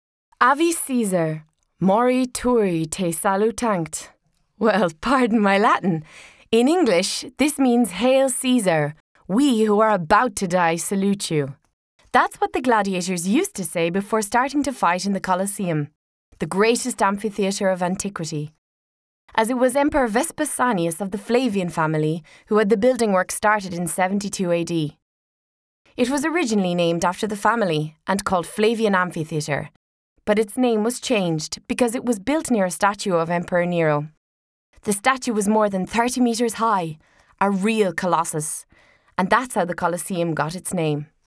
I have a bright friendly voice that can also be quite serious but all the while dynamic and interesting.
Sprechprobe: eLearning (Muttersprache):